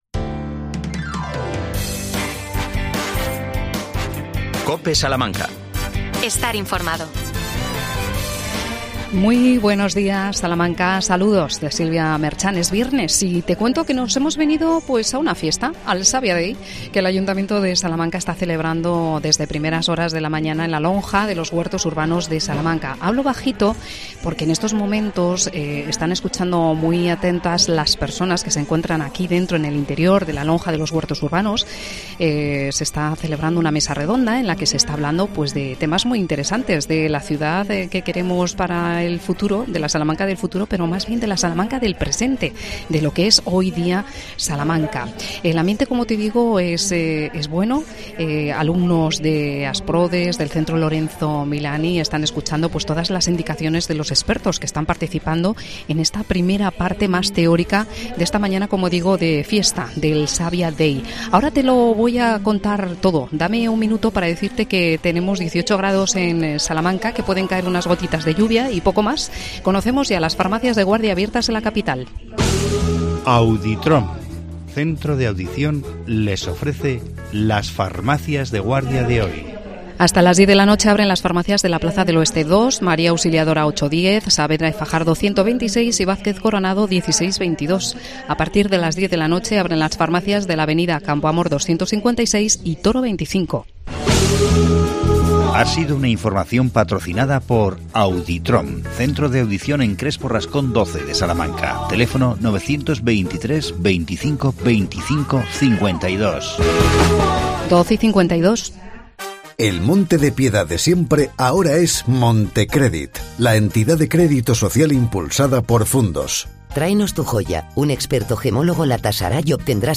AUDIO: COPE Salamanca en el Savia Day.